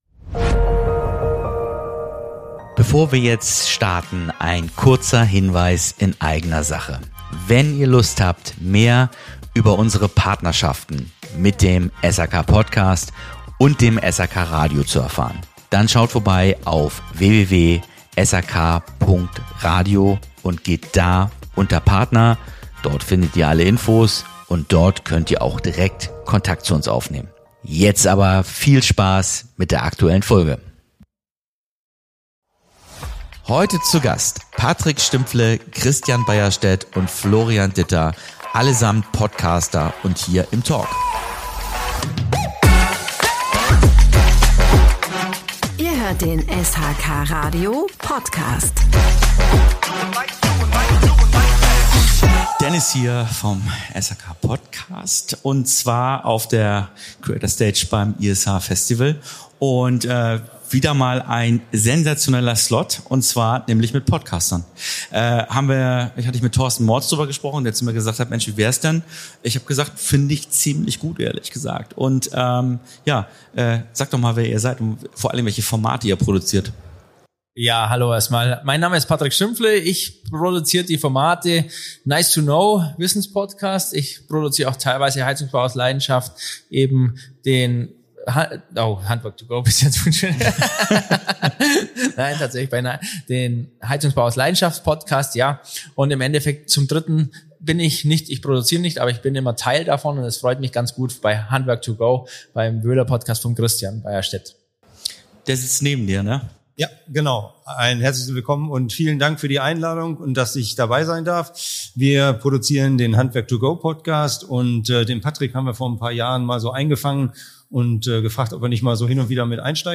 Ein inspirierender, offener Talk über das Podcasten im SHK-Umfeld – echt, praxisnah und mit viel Leidenschaft fürs Handwerk.